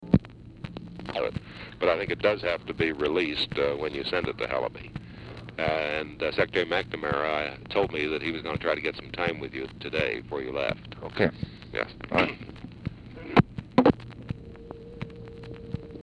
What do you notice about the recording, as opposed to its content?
Format Dictation belt Oval Office or unknown location